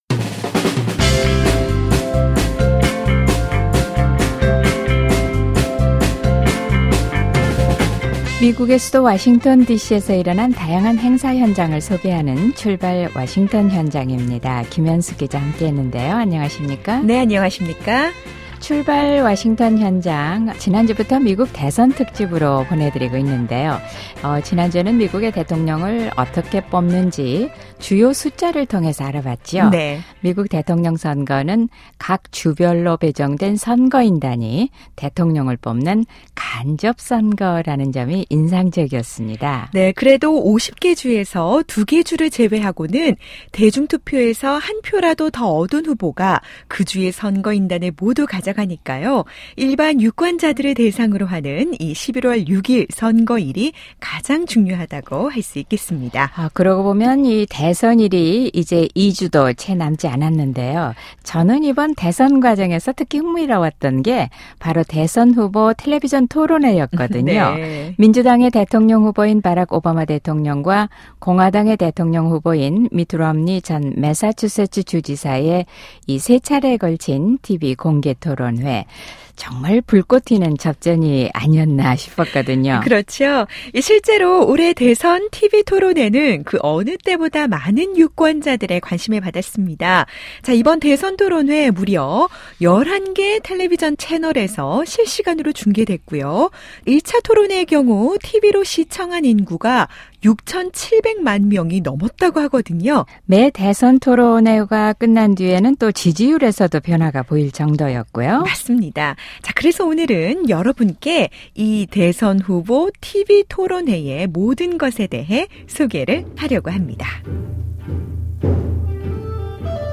대선 후보 토론회의 역사를 짚어보고 또 대선토론회 현장 그리고 대선토론회를 시청하는 현장 등을 찾아가 대선후보 토론회의 그 뜨거운 열기를 느껴봅니다.